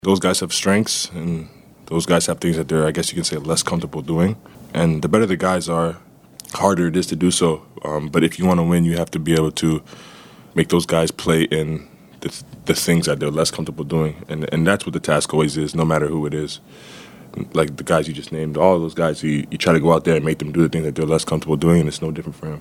Thunder star Shai Gilgeous-Alexander says there are different ways to attack different stars defensively.